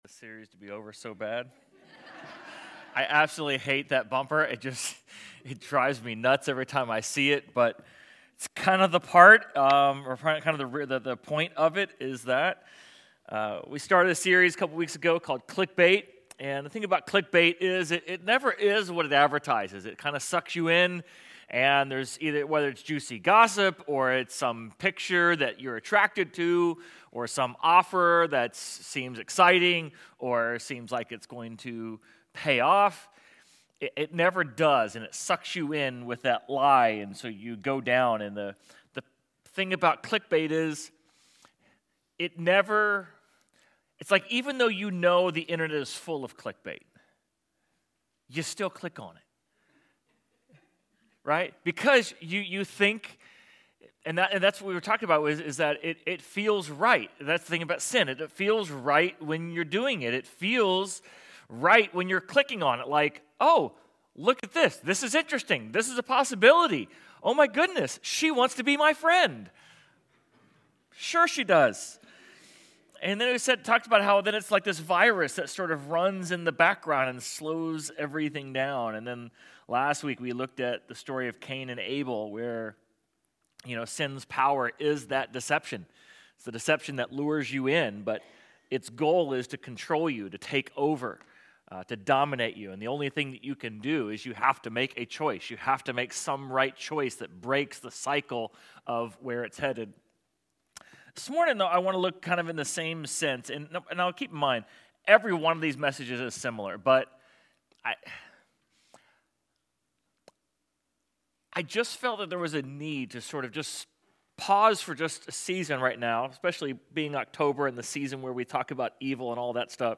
Sermon Audio/Video | Essential Church